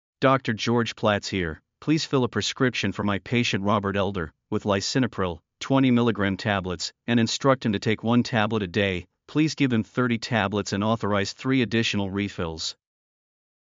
Practice Taking Verbal Prescriptions